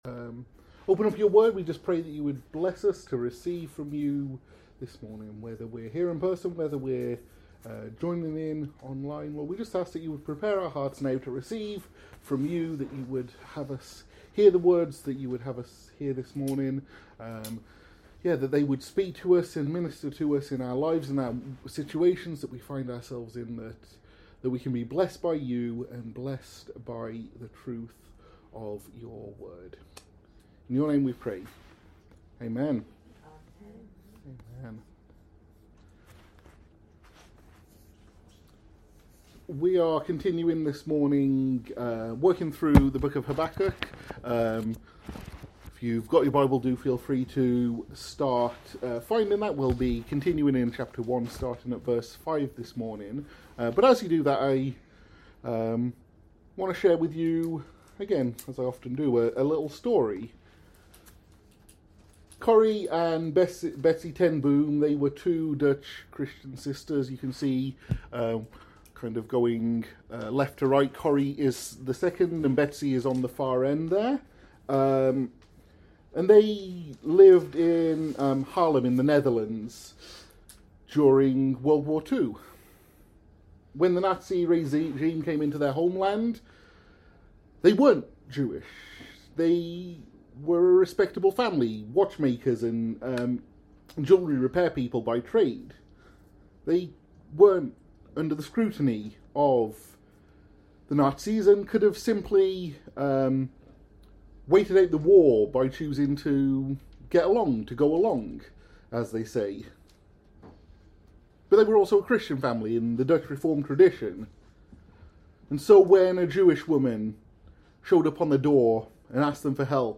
In this expository message on Habakkuk 1:5–11, we’ll explore how God’s astonishing plans can challenge our assumptions and deepen our faith. Discover why He chose a ruthless empire to refine His people, how these truths speak to our own trials, and where we ultimately find hope in Christ’s sacrificial love.